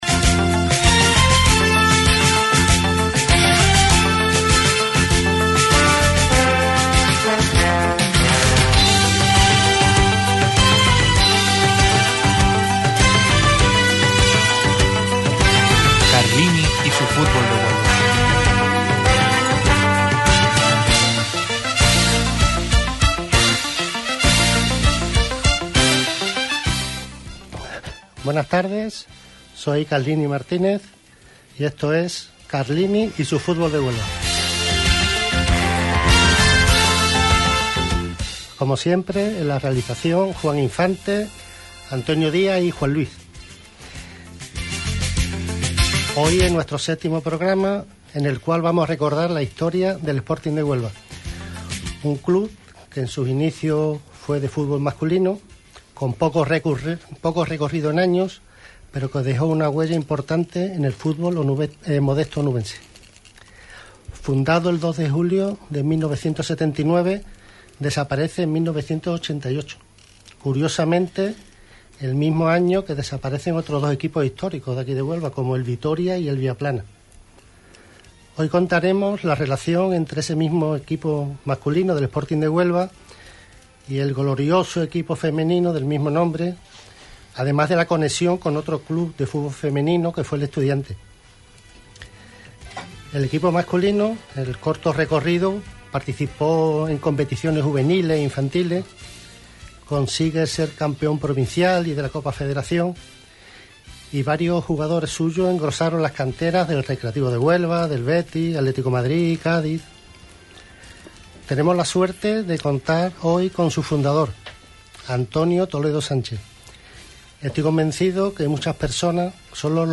Los tertulianos de hoy.